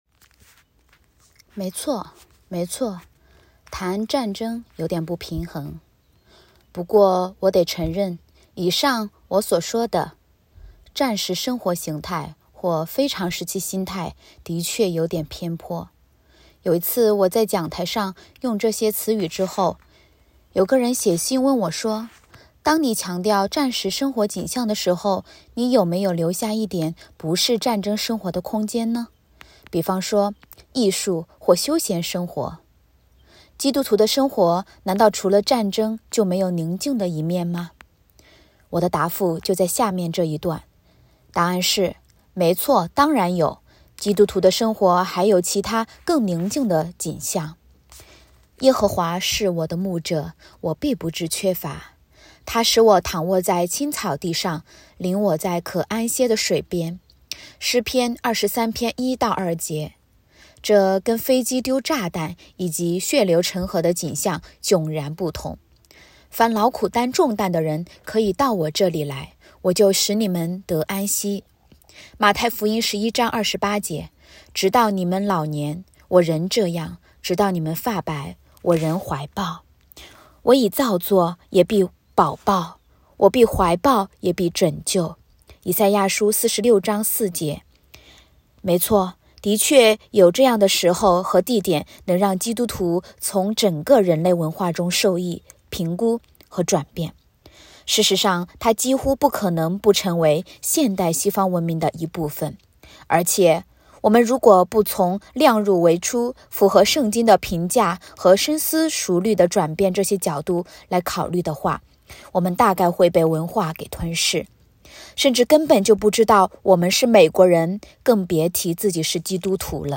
2024年5月23日 “伴你读书”，正在为您朗读：《活出热情》 欢迎点击下方音频聆听朗读内容 https